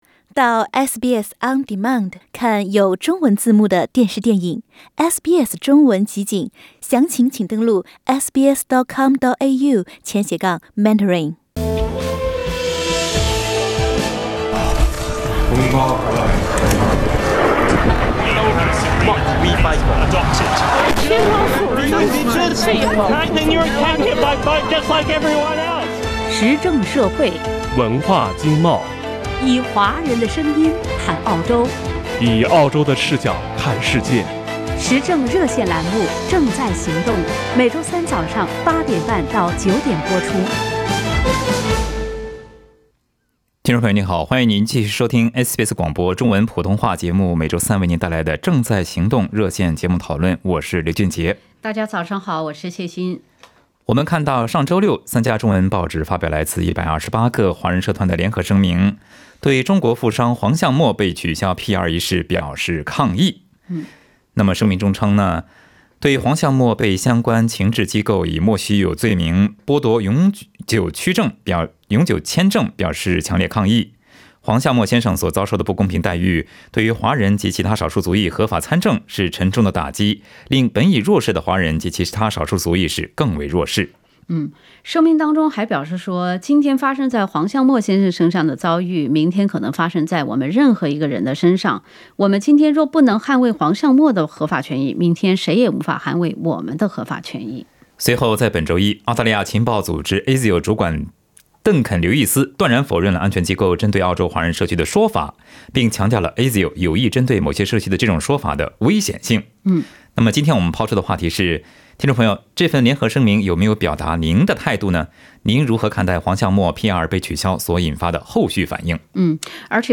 本期《正在行动》节目讨论中，不少听众表达了他们的看法。